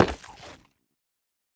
Minecraft Version Minecraft Version latest Latest Release | Latest Snapshot latest / assets / minecraft / sounds / block / nether_ore / step4.ogg Compare With Compare With Latest Release | Latest Snapshot
step4.ogg